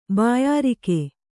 ♪ bāyārike